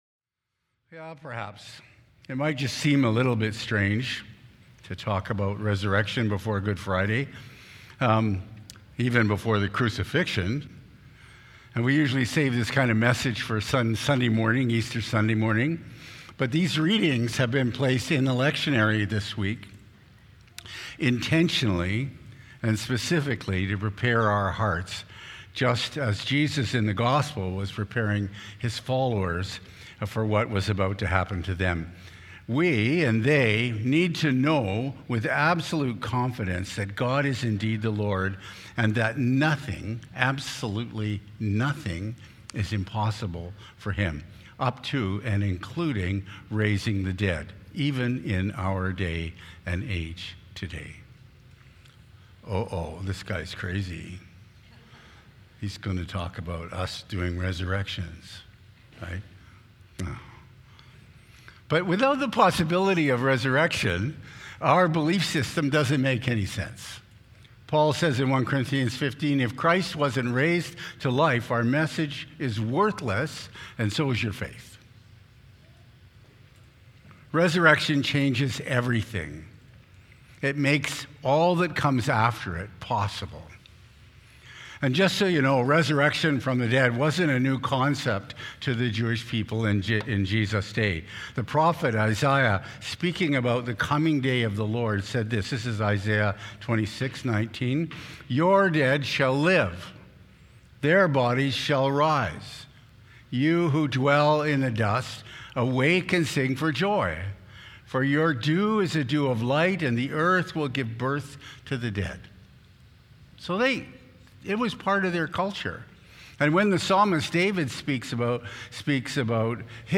Sermons | Emmaus Road Anglican Church